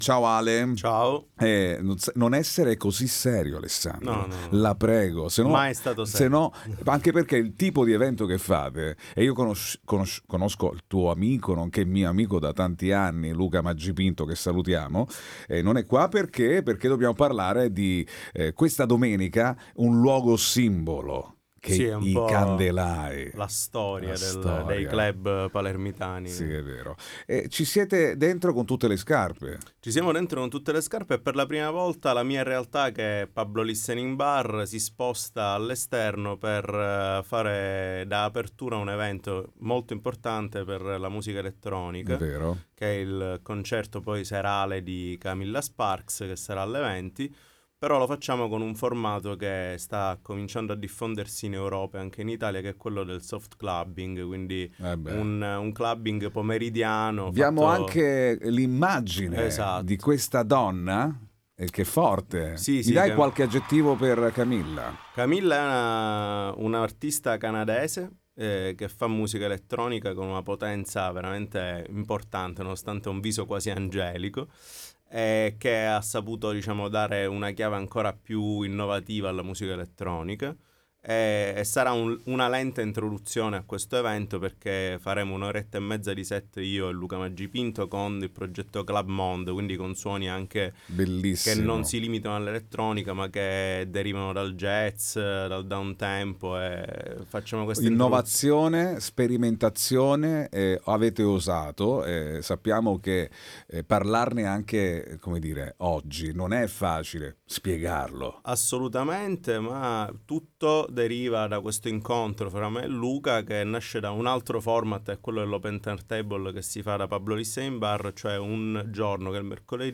All Inclusive Interviste